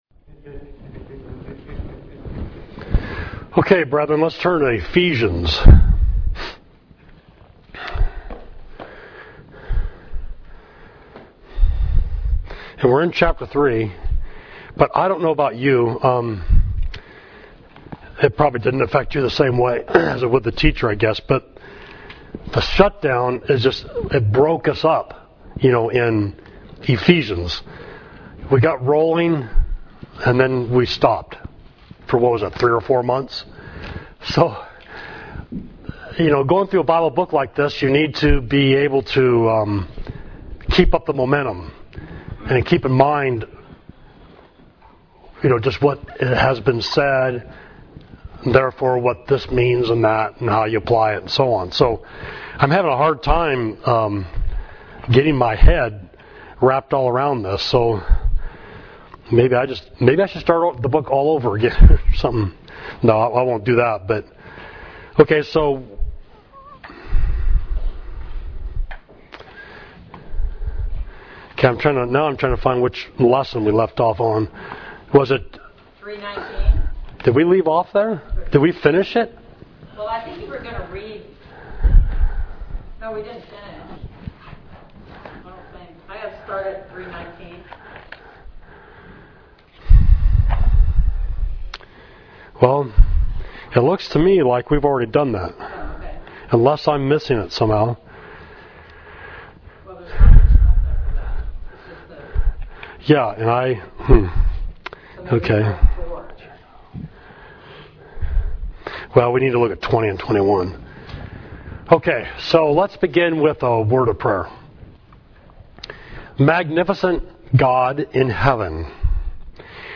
Class: Ephesians 3.20–4.6